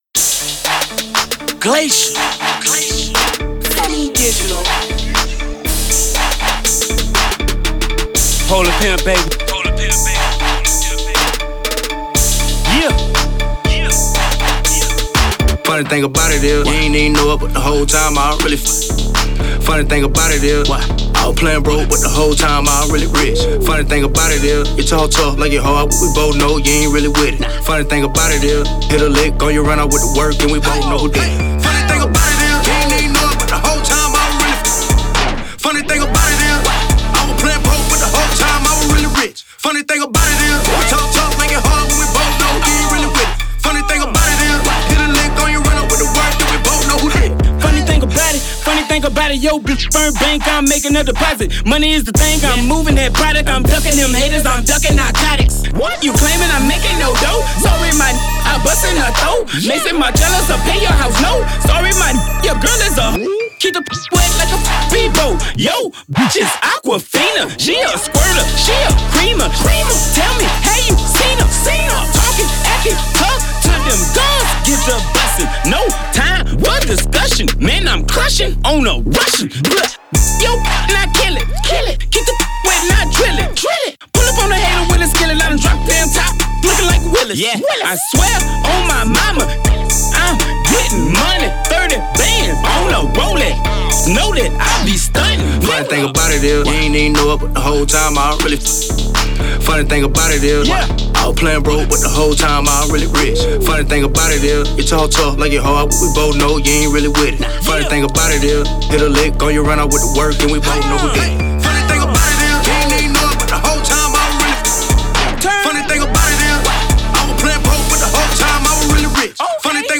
THIS RECORD IS STRONG, POWERFUL, AND A DEFINITE BANGER.